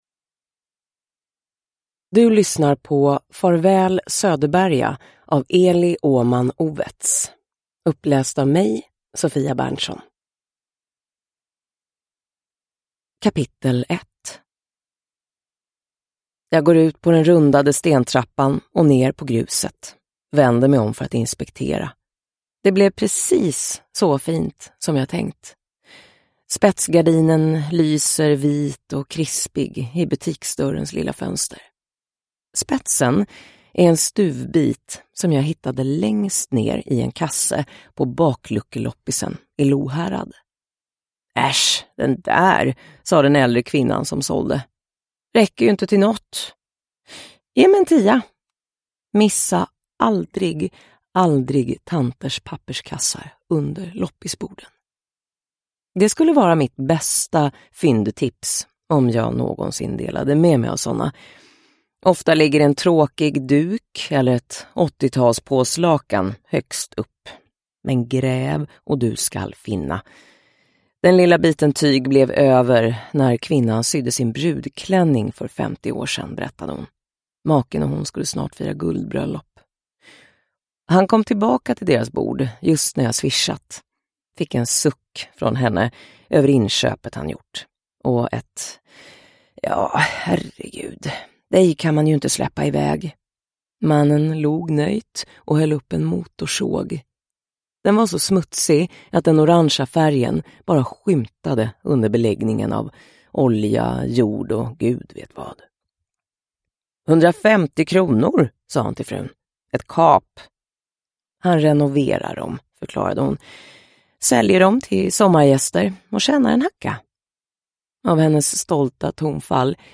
Farväl, Söderberga (ljudbok) av Eli Åhman Owetz | Bokon